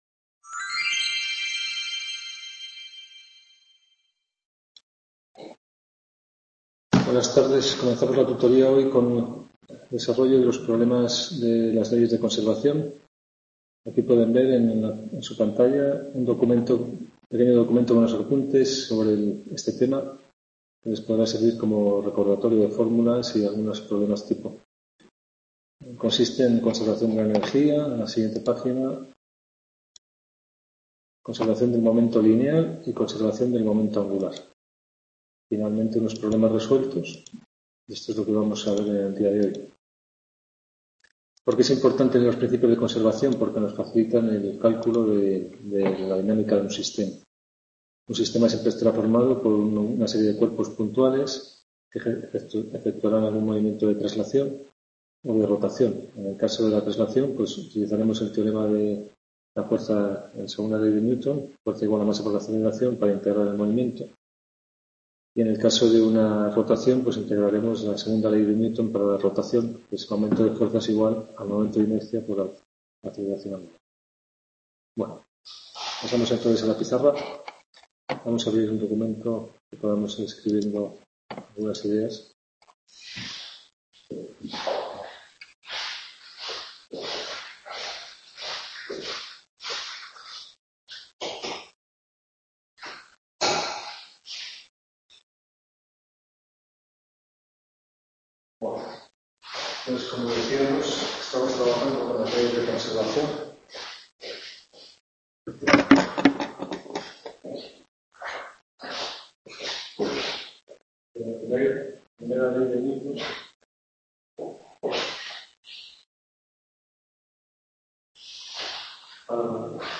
Tutoria 8 marzo 2017 | Repositorio Digital